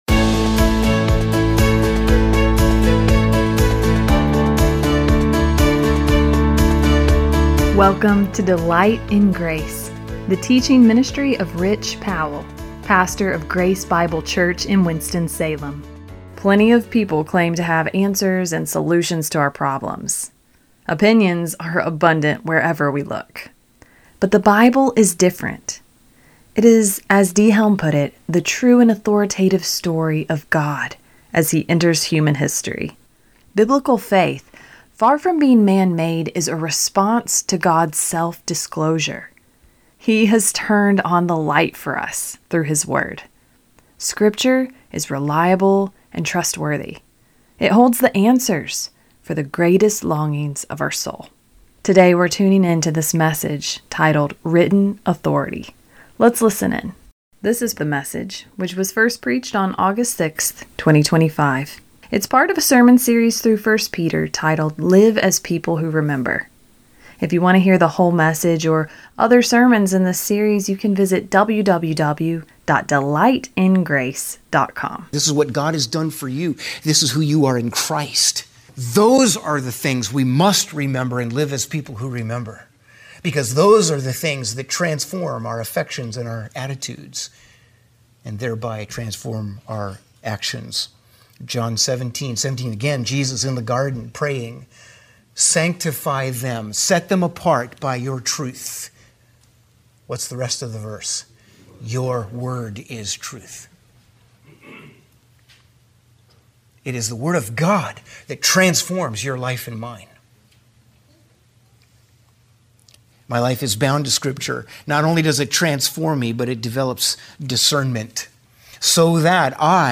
This is part 2 of the message first preached on August 6th, 2025 at GBC. It is part of a sermon series through 1 Peter titled Live as People who Remember.